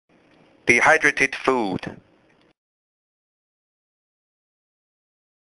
這才是道地的美式發音喔！
這個字的重讀母音很多。注意重音在第二音節。